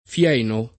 fieno [ f L$ no ] s. m.